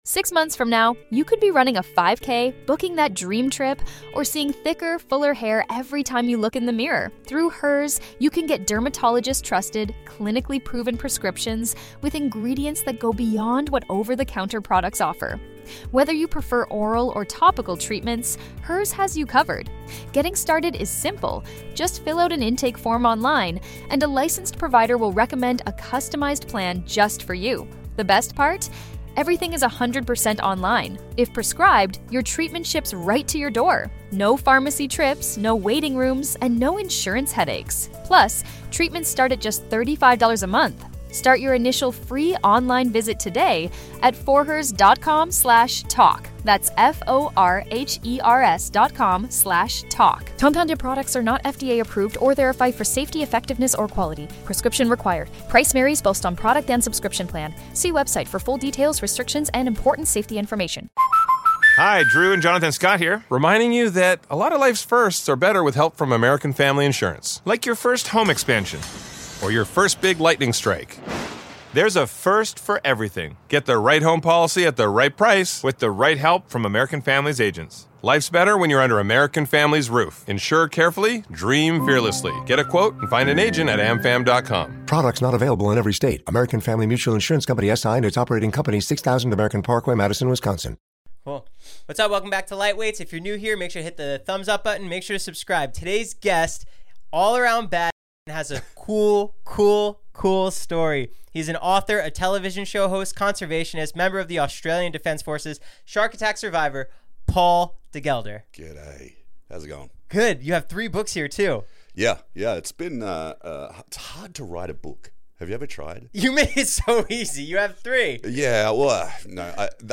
Society & Culture, Tv Reviews, Comedy Interviews, Film Interviews, Comedy, Tv & Film, Education, Hobbies, Music Commentary, Music Interviews, Relationships, Leisure, Health & Fitness, Self-improvement, Music, Fitness